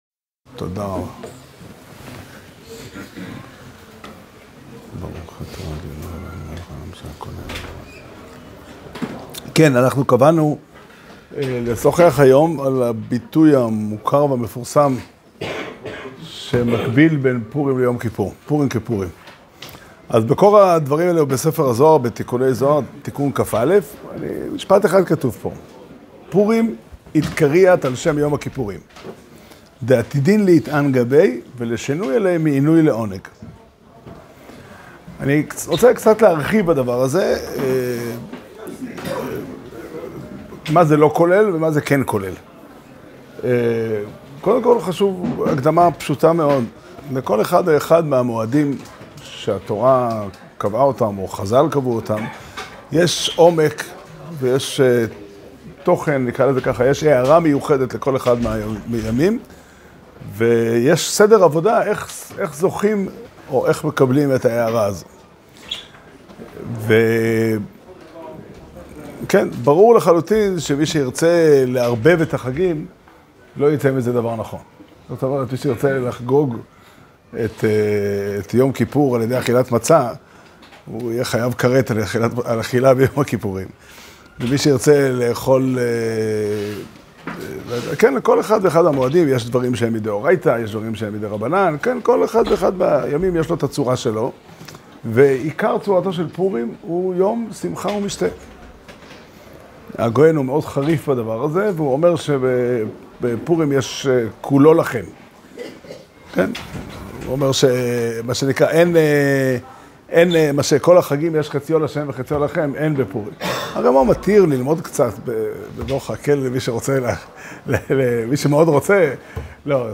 שיעור שנמסר בבית המדרש פתחי עולם בתאריך ט' אדר תשפ"ה